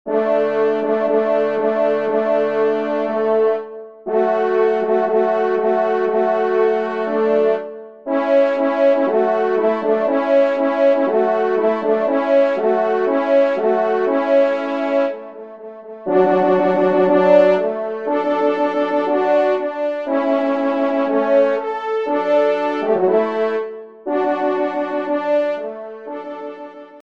3ème Trompe